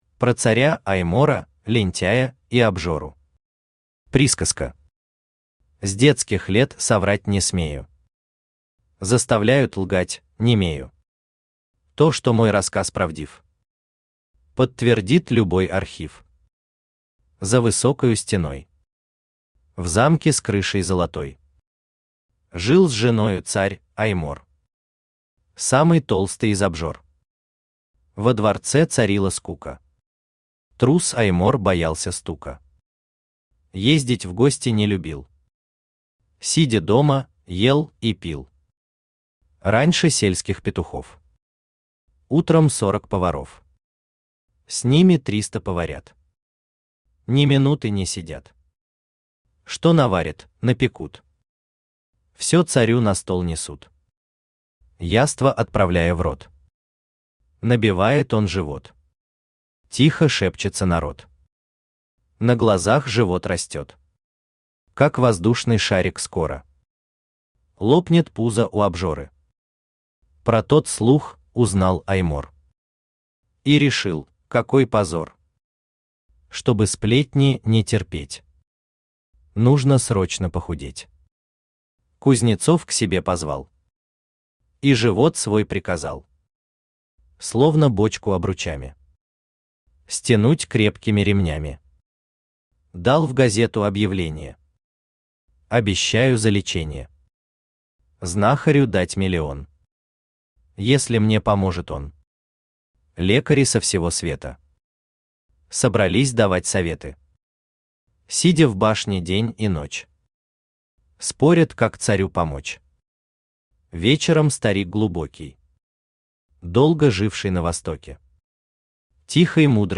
Аудиокнига Про царя Аймора, лентяя и обжору | Библиотека аудиокниг
Aудиокнига Про царя Аймора, лентяя и обжору Автор Николай Николаевич Самойлов Читает аудиокнигу Авточтец ЛитРес.